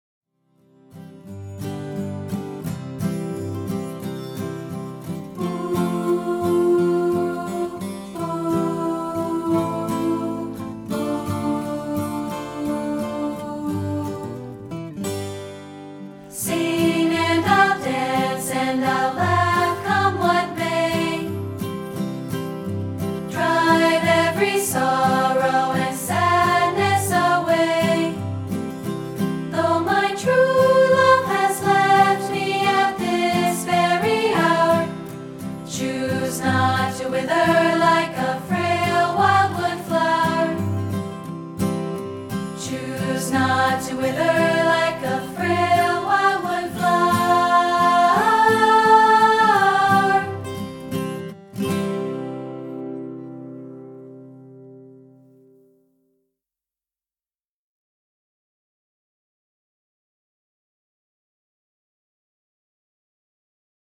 We're offering a rehearsal track of part 2, isolated